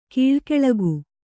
♪ kīḷkeḷagu